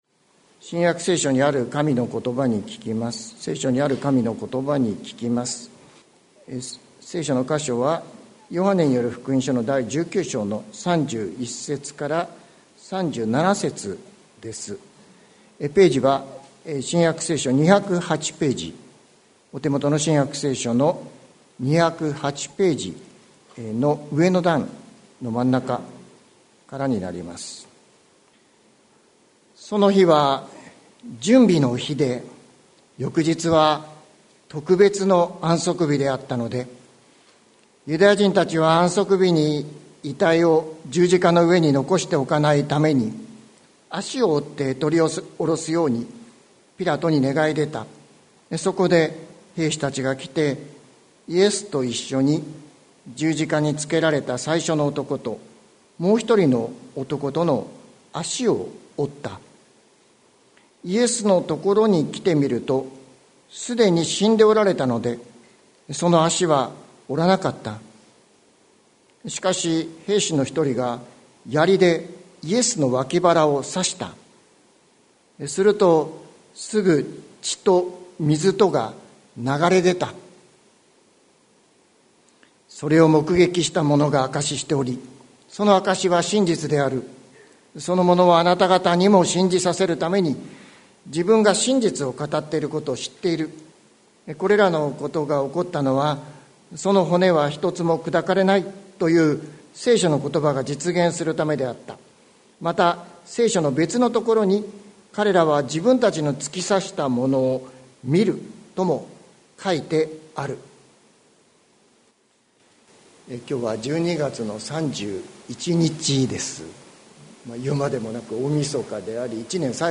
2023年12月31日朝の礼拝「十字架の恵み」関キリスト教会
説教アーカイブ。